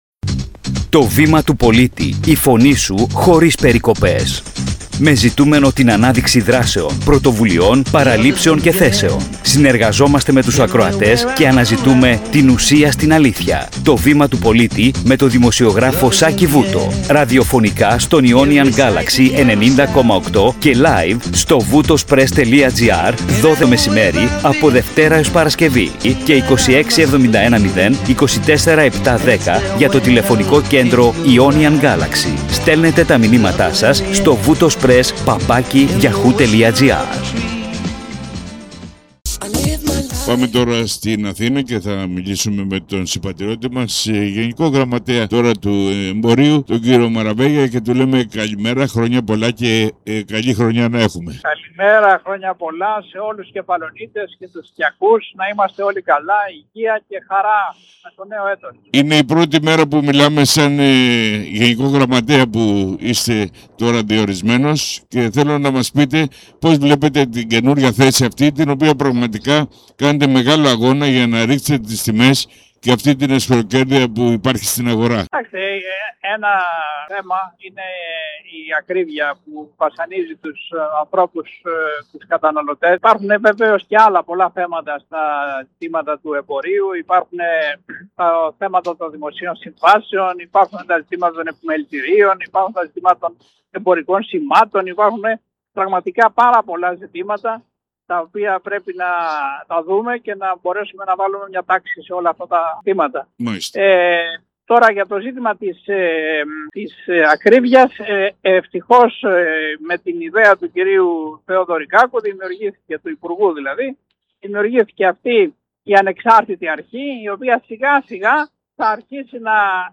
Σε ζωντανή σύνδεση με την Αθήνα, ο συμπατριώτης μας Ναπολέων Μαραβέγιας, νεοδιορισμένος Γενικός Γραμματέας Εμπορίου, μίλησε στην εκπομπή «Το Βήμα του Πολίτη»